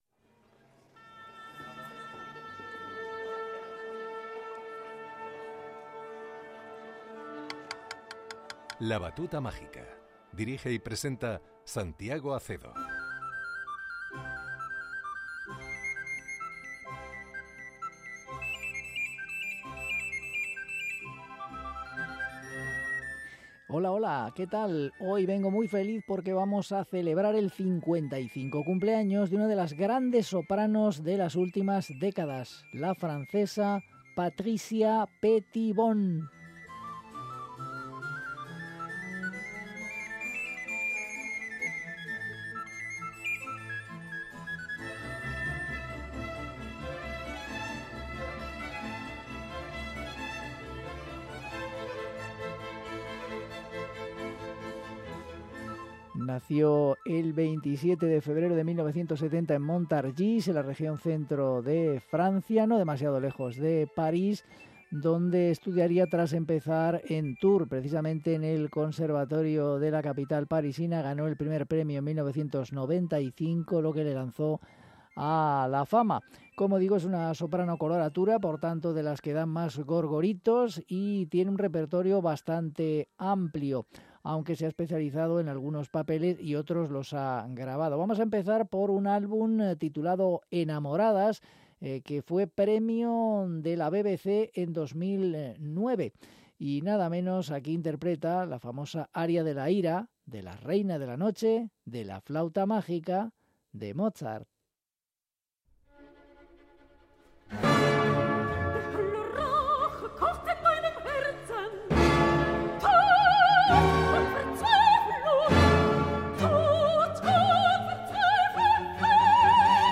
soprano
Vocalise para soprano